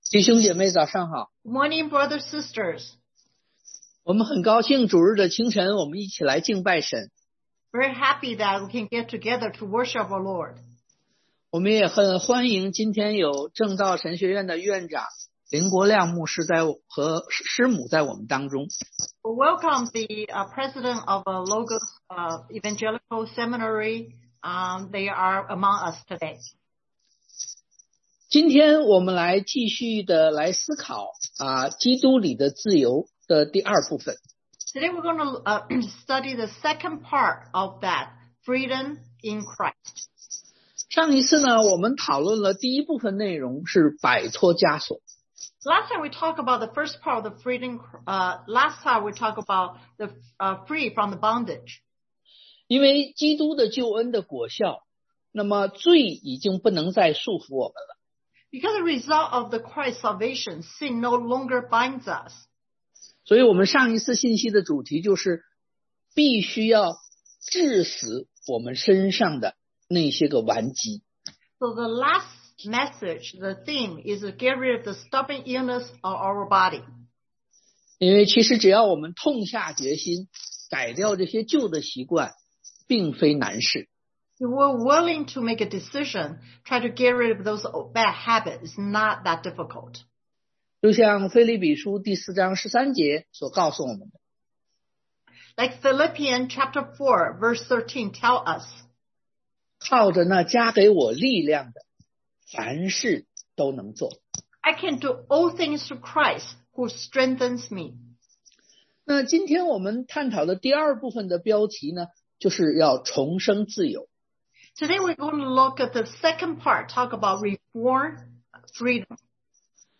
Passage: Gal 5:1-12 Service Type: Sunday AM